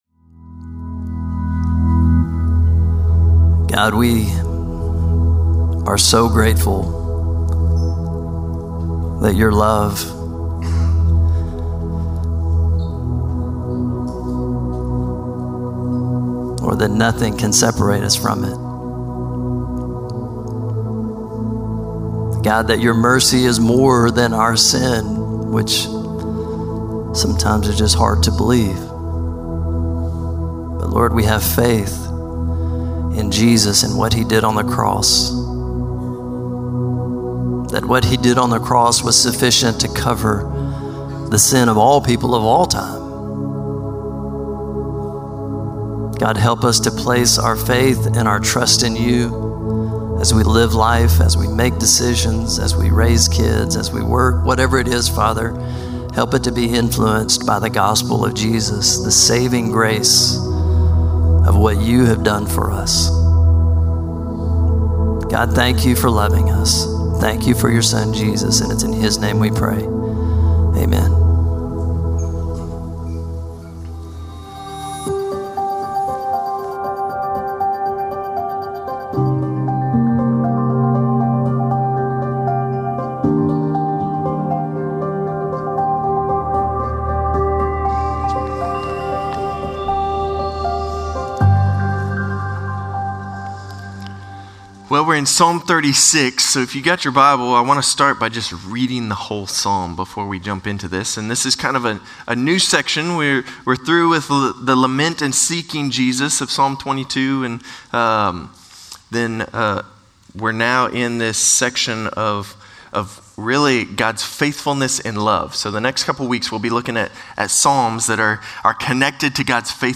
Norris Ferry Sermons Jan. 5, 2025 -- The Book of Psalms -- Psalm 36 Jan 12 2025 | 00:33:11 1x 00:00 / 00:33:11 Subscribe Share Spotify RSS Feed Share Link Embed